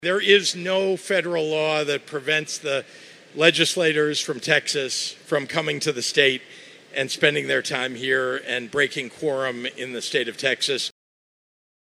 (Springfield, IL)  —  Governor Pritzker is vowing to protect Texas House Democrats who fled to Illinois from Texas authorities.  Speaking yesterday at the Illinois State Fairgrounds in Springfield, Pritzker said Texas rangers are welcome to visit the state of Illinois, but they can’t arrest anyone and take them away.